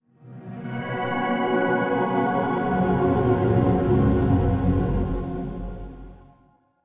OS3 Warp 4.0 Shutdown.wav